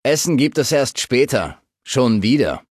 Datei:Maleadult01default convandale hello 0001b03d.ogg
Fallout 3: Audiodialoge